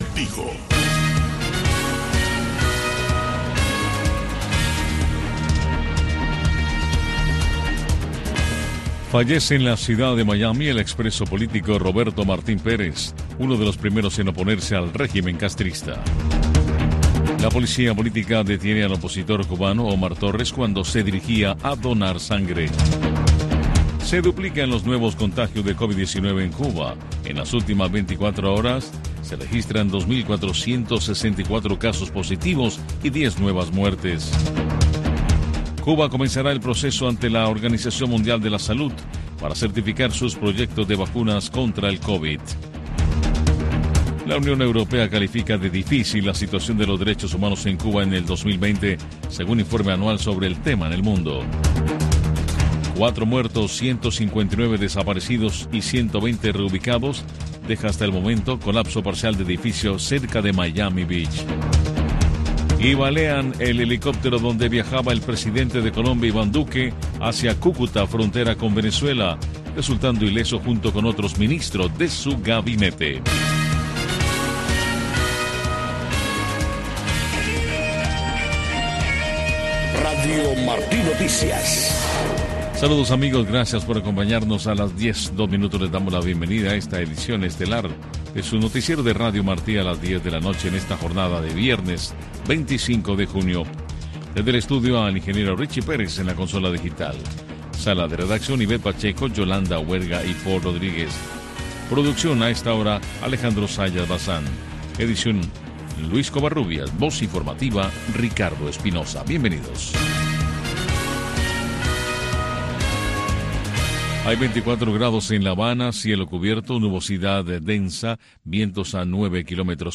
Noticiero de Radio Martí 10:00 PM